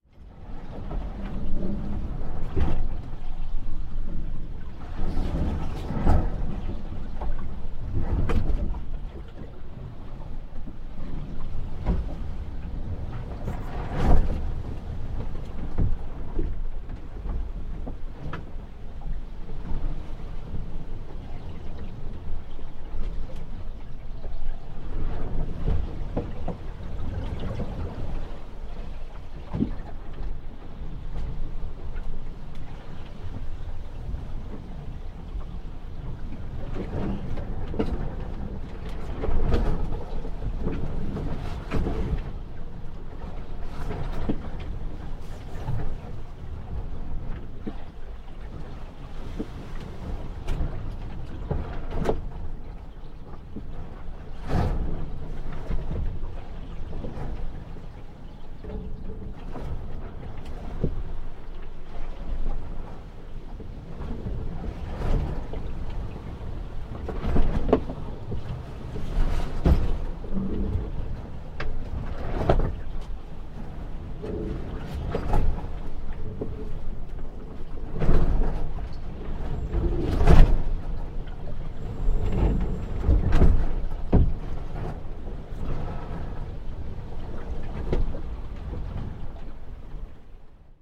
SailboatBelowDeck
Category: Sound FX   Right: Personal
Tags: Dungeons and Dragons Sea Ship